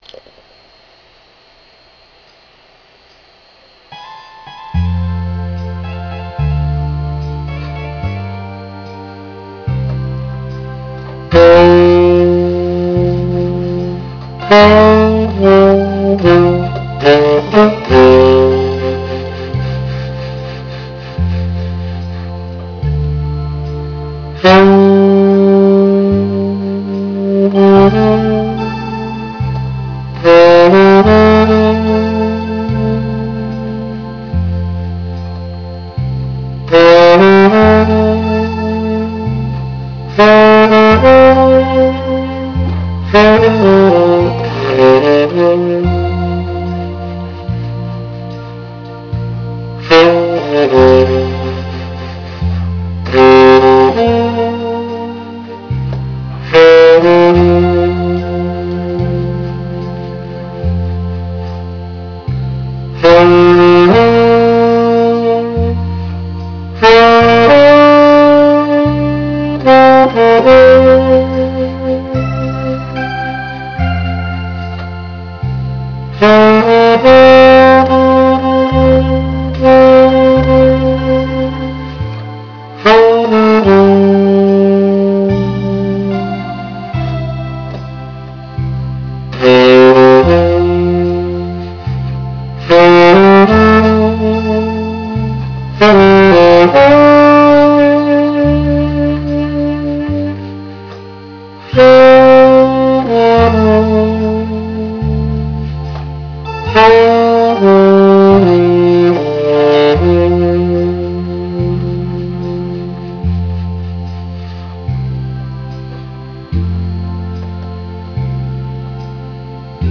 아마추어의 색소폰 연주
이제 앰프와 스피커가 있어야 되는데 없으니 우선 집에서 그냥 컴퓨터용 스피커에 맞추어 불어봤습니다
혹시 또 먼저처럼 윗집에서 �아올까봐 살살 조심조심.....
왕초보 연주 이해하고 들어주시길....
* 테너 야마하 275, 모건엑스컬리버, 반도렌1/2호, 은성워크미디어